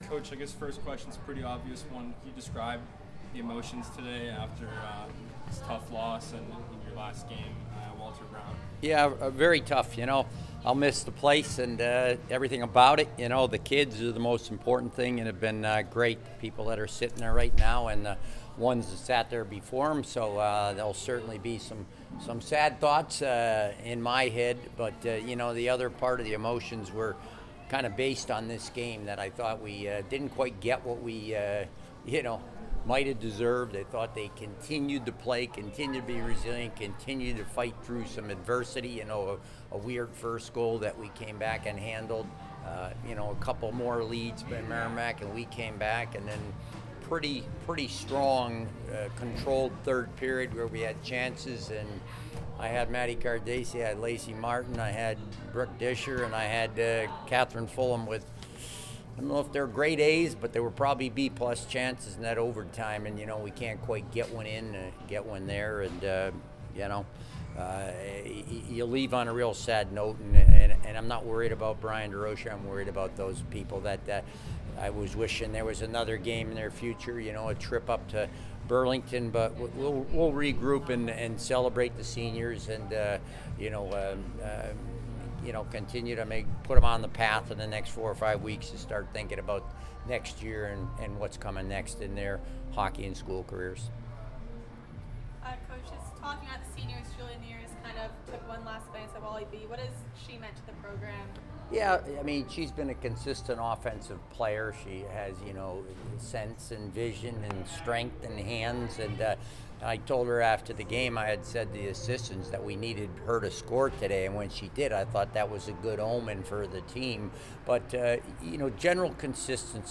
Women's Ice Hockey / Merrimack Postgame Interview